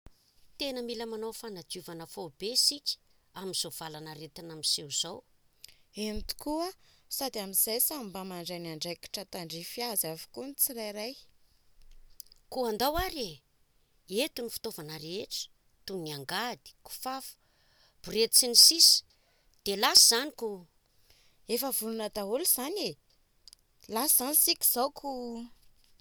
PH: Téna mil manaou fanadiouvana, faoubé sika am’zaou valan’arétin miséou ‘zaou!